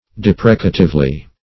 deprecatively.mp3